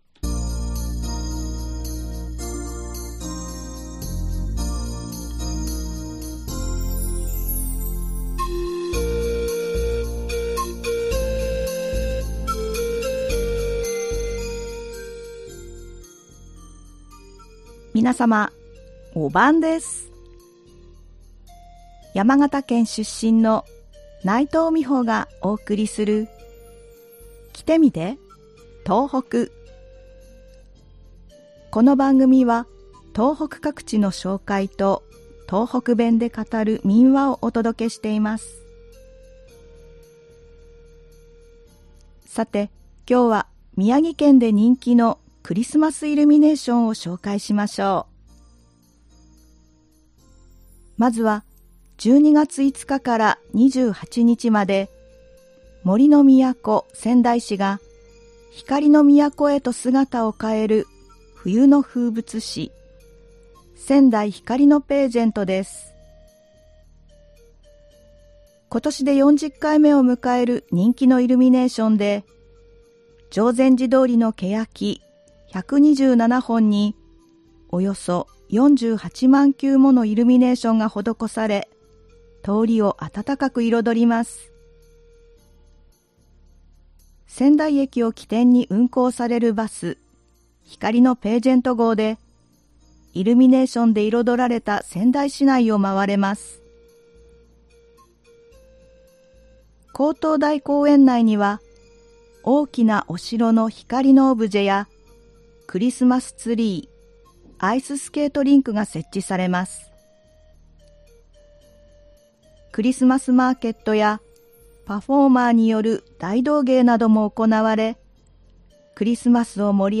この番組は東北各地の紹介と、東北弁で語る民話をお届けしています。
ではここでから、東北弁で語る民話をお送りします。今回は宮城県で語られていた民話「きつねとたぬき」です。